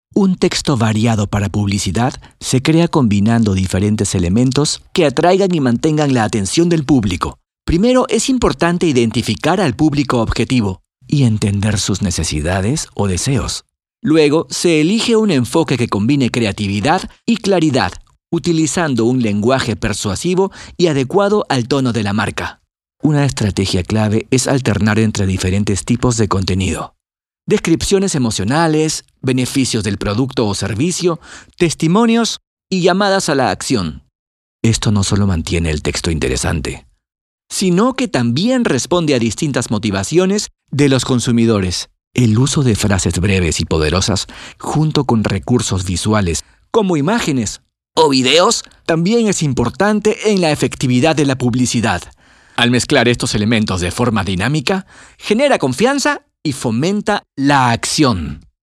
E-Learning
Ich habe mein eigenes Studio.